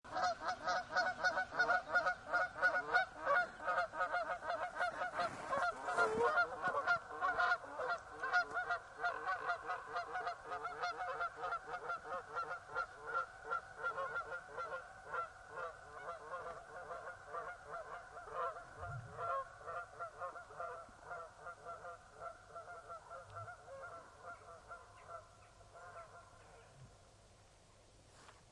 Geese Fly Overhead Bouton sonore